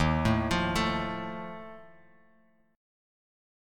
Ebsus2b5 chord